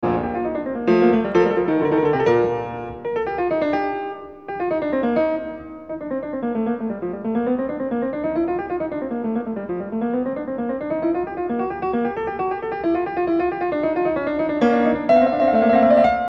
تجدون هنا الأداجيو معزوفةً من ألفريد بريندل، و كذلك المقاطع المقتبسه القادمه هي من نفس التسجيل :
و هكذا يستمر بالتبديل بين المفاتيح حتى يصل الى المفتاح الأصلي لهذه السوناتا B♭major عندها يعزف اللحن الرئيسي للفيوچ، ذلك اللحن الذي لايضاهي اي لحن فيوچي آخر، صعب للغايه و طويل بحيث يصل الى أحد عشرة مازوره، كروماتيكي (لايستند الى مفتاح معين)، و عوضا عن ذلك يبدأ هذا اللحن بالتريلز، هذه الاداه التي لاتزيد على ان تكون زخرفة ثانويه، جعلها بيتهوڤن عنصراً أساسياً للحن فيوچي ! ، بهذا تفوق بيتهوفن على باخ الماستر الأول لقالب الفيوچ.
hammerklavier-fugue-theme.mp3